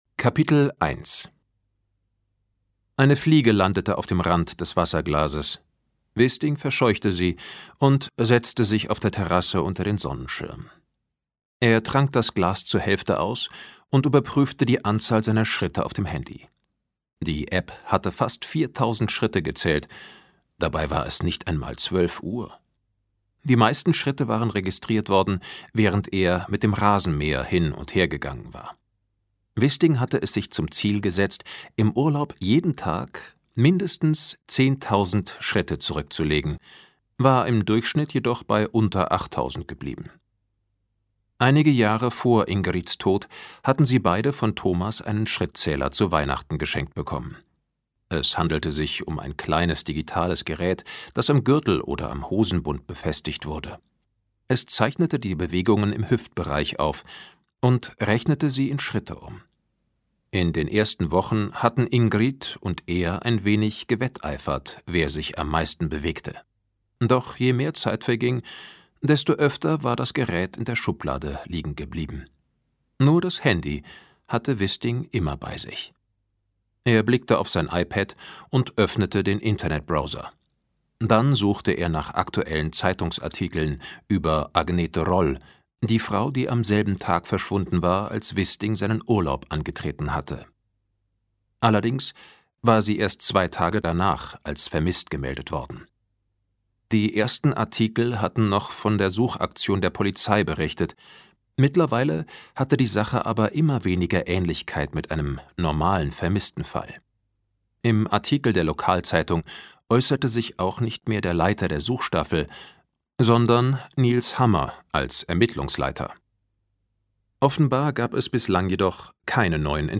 Ungekürzt gelesen von Götz Otto.
Ungekürzte Lesung